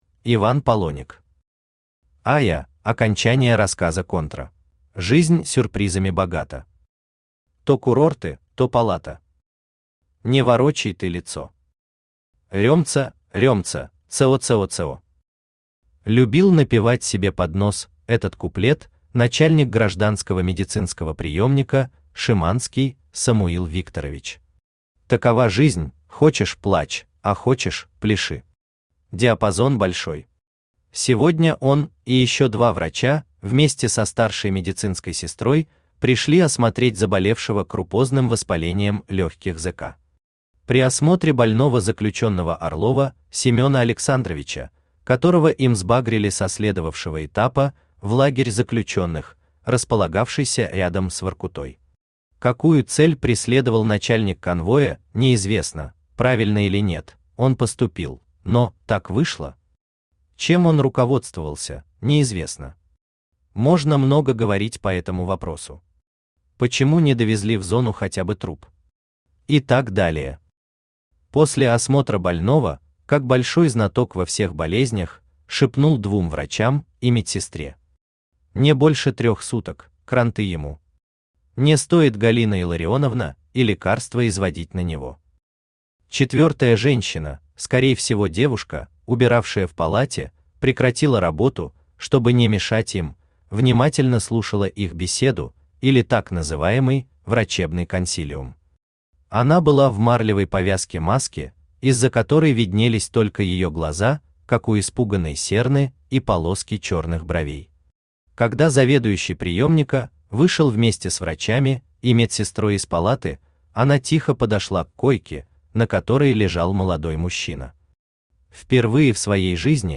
Аудиокнига Айя (окончание рассказа «Контра») | Библиотека аудиокниг